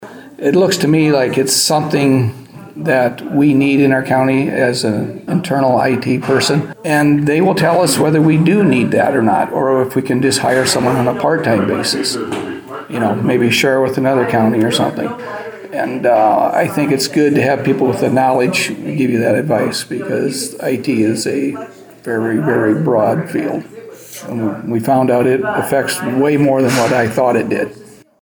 Humboldt County Board of Supervisors Chairman Rick Pedersen says there is a need for an IT person in the county.